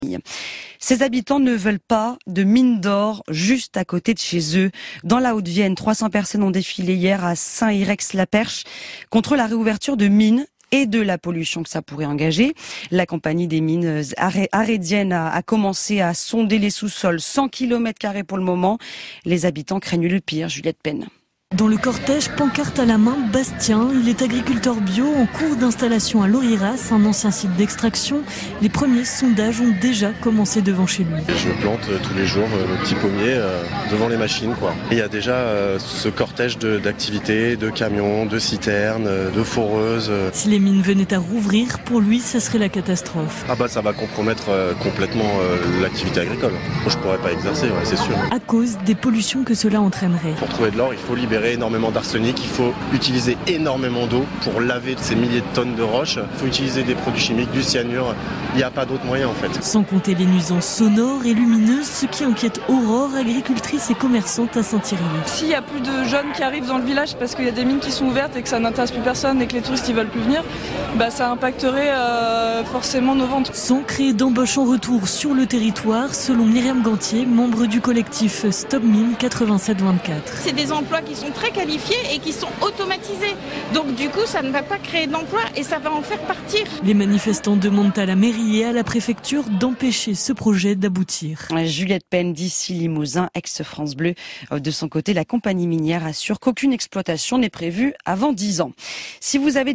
ICI Limousin_Le journal de 08h00 du dimanche 20 avril 2025 France Inter